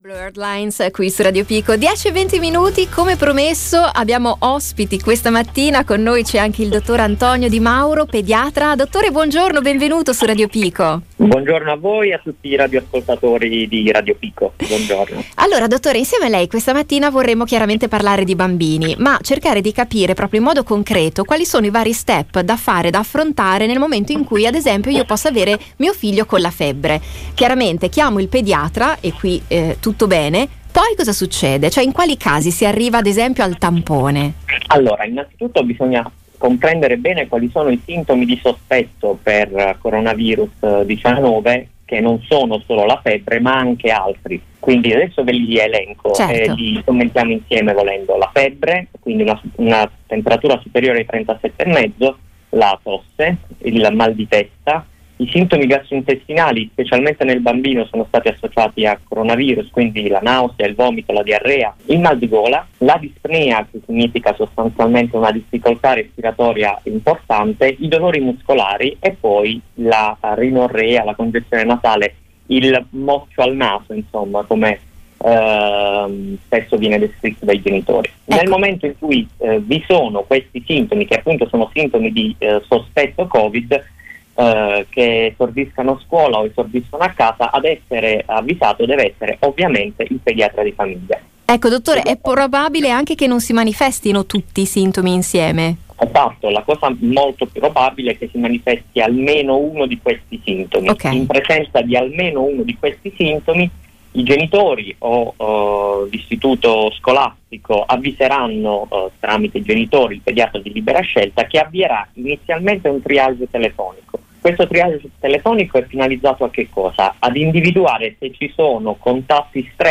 Per questo motivo abbiamo voluto intervistare il pediatra